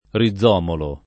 rizomolo [ ri zz0 molo ] → rindomolo